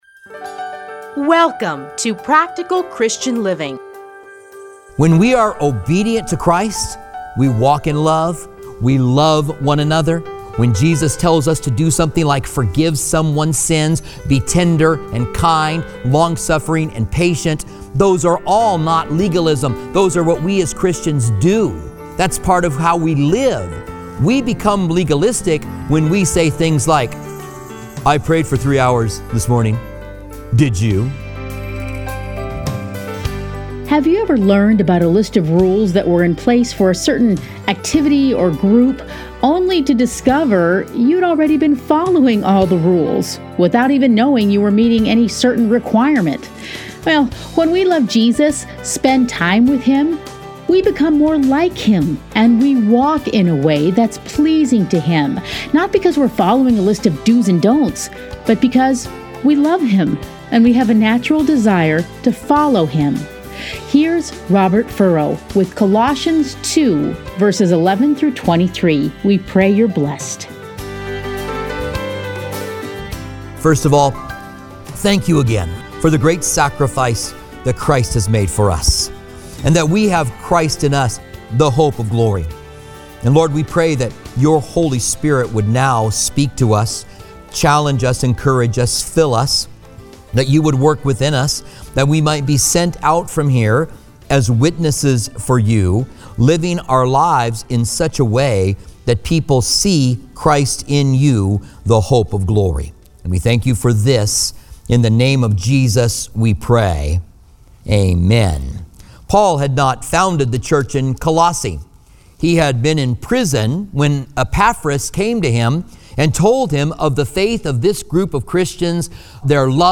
Listen here to a teaching from Colossians.